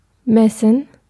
Ääntäminen
Synonyymit metric instrument bar bill proceeding Ääntäminen US Tuntematon aksentti: IPA : /ˈmɛʒə/ IPA : /ˈmɛʒ.əɹ/ Haettu sana löytyi näillä lähdekielillä: englanti Käännös Konteksti Ääninäyte Substantiivit 1.